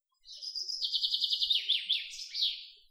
birds01.wav